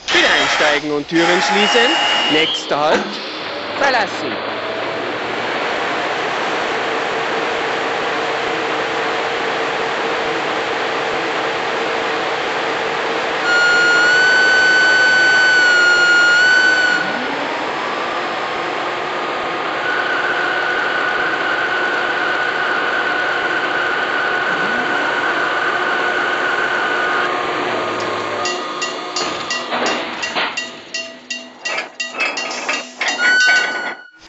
Märklin 37480 Demo-Sound.mp3